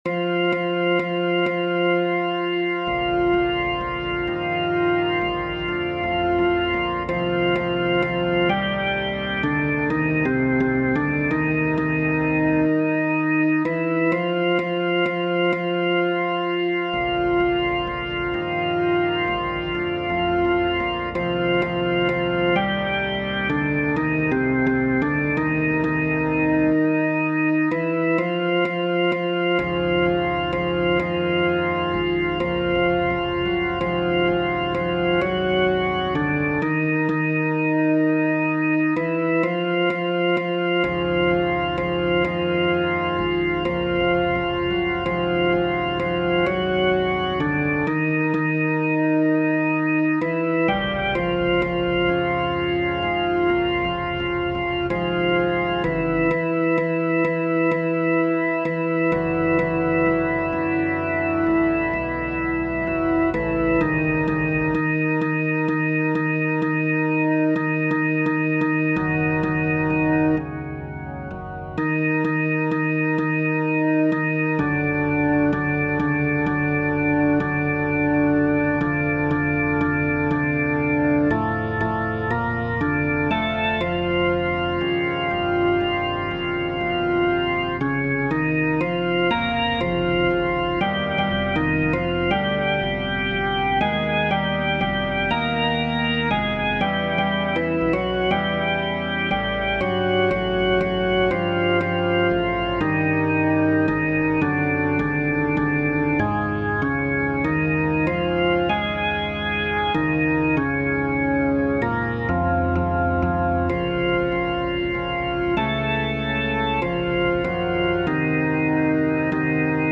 FF:HV_15b Collegium male choir
Kladeni-T1.mp3